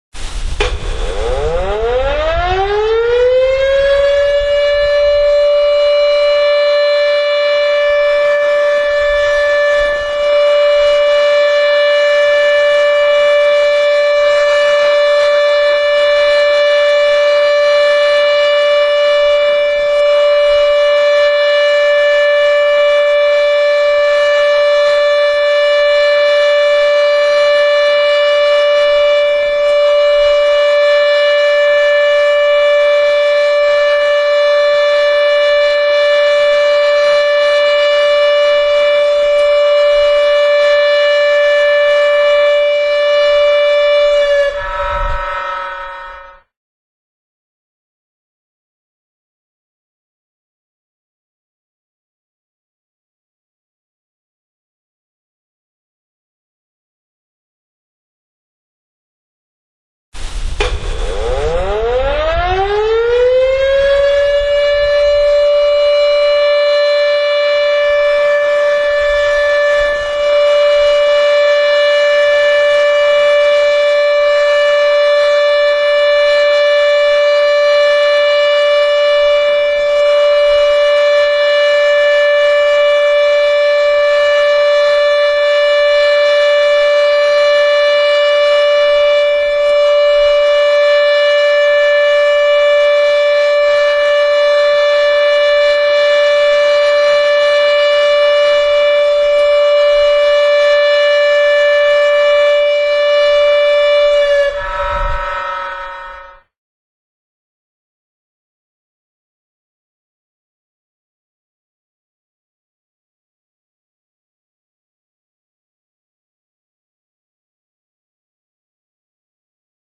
災害時などには昼夜問わず大きなサイレン音が鳴り、付近住民の皆さんには大変ご迷惑をおかけいたしますが、ご理解ご協力をお願いします。
サイレン信号の種類
火災等避難勧告及び指示
人的被害の発生する可能性が高まり、地域住民に安全な場所に避難等するよう知らせる時に吹鳴します。   45秒－休止15秒－45秒－休止15秒－45秒－休止15秒－45秒